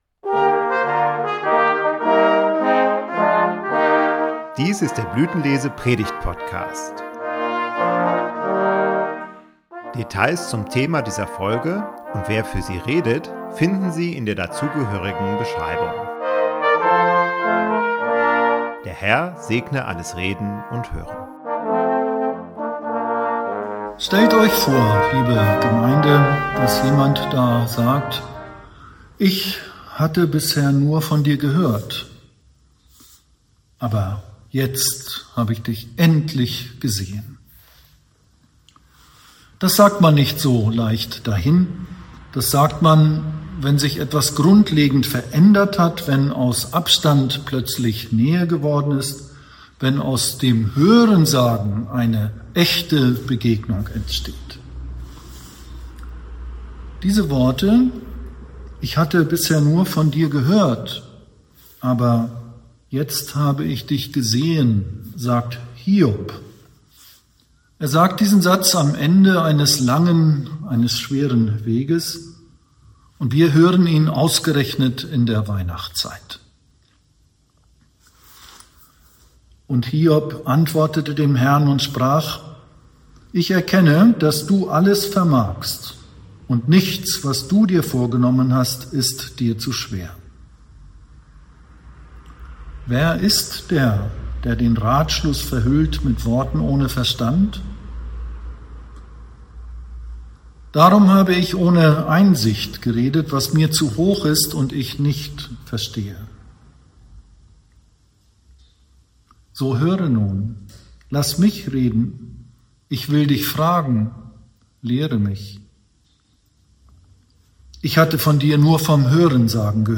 Vom Hörensagen zum Sehen ~ Blütenlese Predigt-Podcast Podcast
Dezember 2025 16 Minuten 15.11 MB Podcast Podcaster Blütenlese Predigt-Podcast Predigten aus dem Blütenlese Video-Gottesdienst Religion & Spiritualität Folgen 0 Podcast aneignen Beschreibung vor 2 Monaten Hiob?